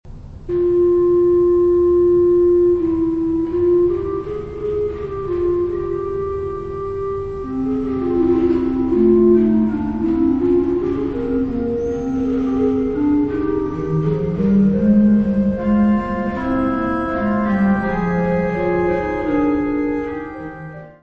: stereo; 12 cm
Orgão histórico da Abbaye de Saint-Michel en Thiérache
orgão
Music Category/Genre:  Classical Music